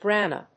/grˈæmə(米国英語), grάːmə(英国英語)/